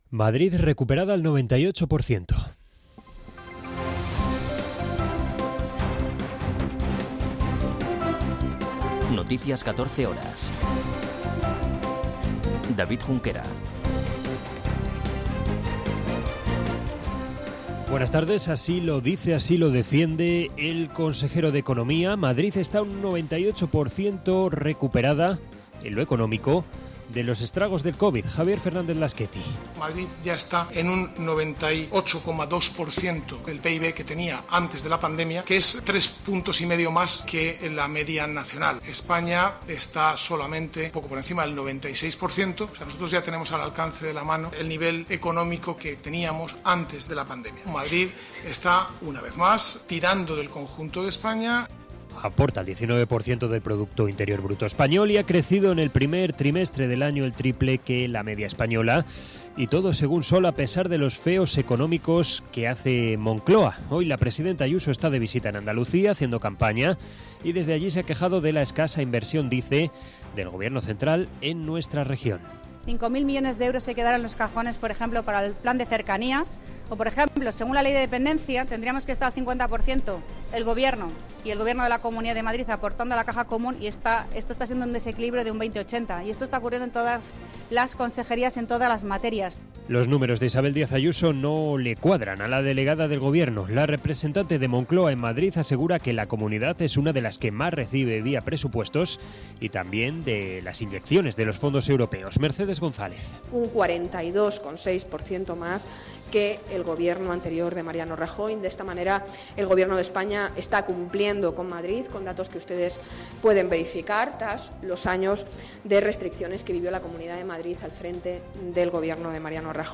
Noticias 14 horas 31.05.2022